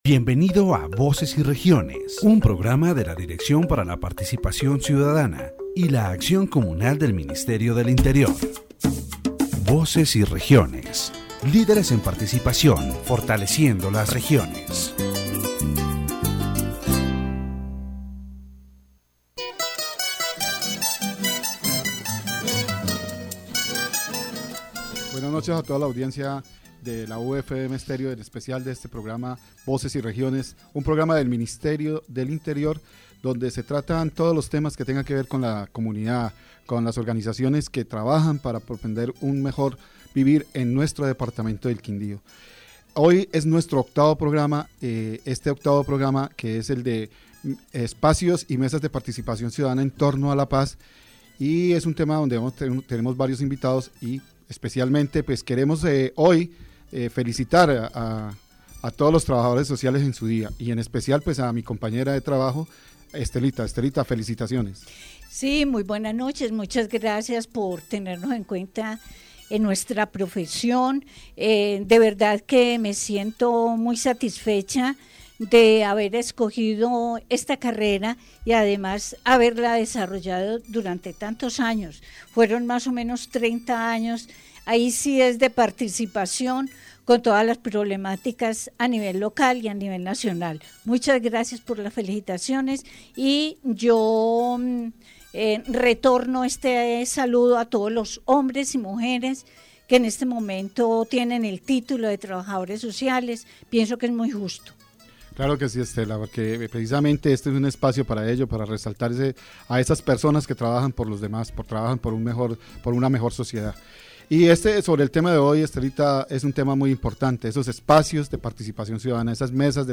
In this section of the Voces y Regiones program, the topic of the Peace Tables in Armenia, Quindío is discussed. The interviewee highlights the concerning situation of young people in vulnerable conditions, emphasizing how the lack of access to education and technology increases the risk of them being drawn into environments of violence and social exclusion.